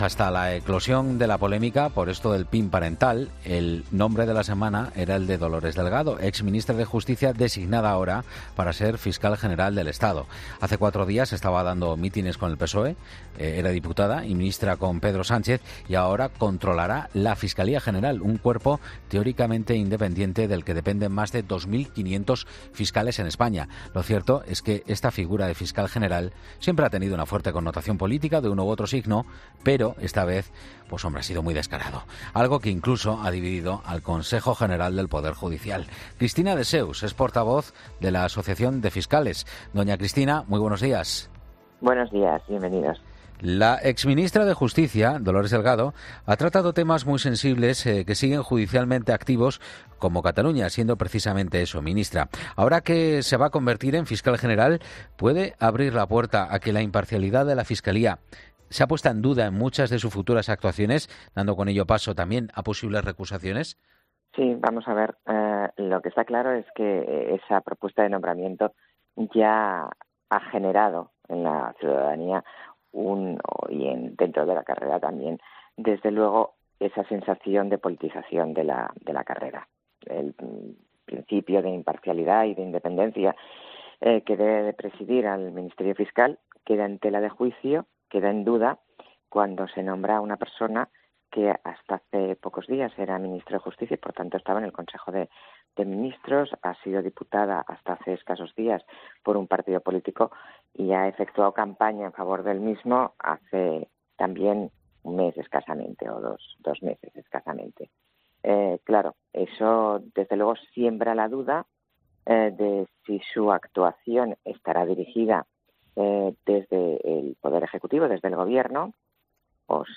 ha sido entrevistada en 'La Mañana Fin de Semana' al respecto del caso Dolores Delgado